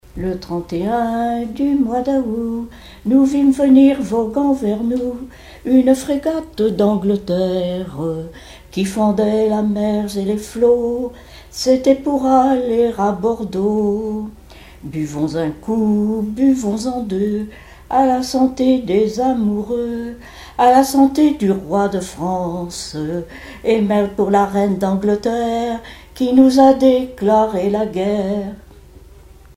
Mémoires et Patrimoines vivants - RaddO est une base de données d'archives iconographiques et sonores.
Genre strophique
Chansons et témoignages
Pièce musicale inédite